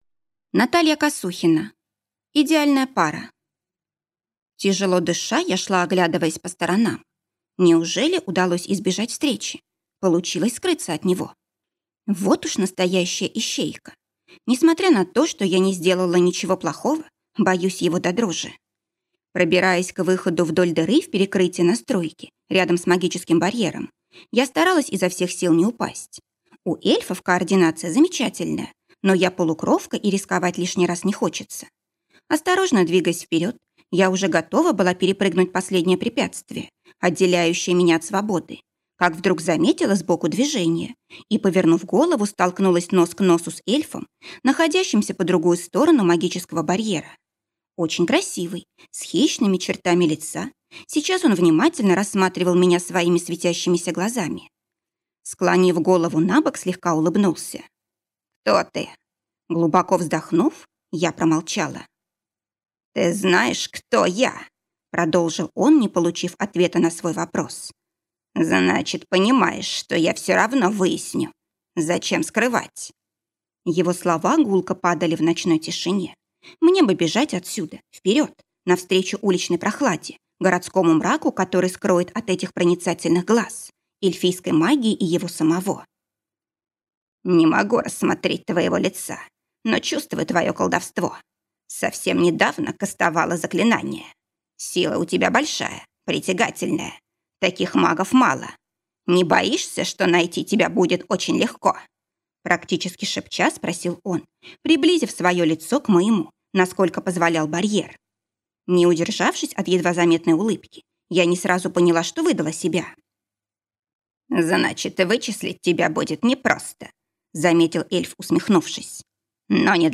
Аудиокнига Идеальная пара | Библиотека аудиокниг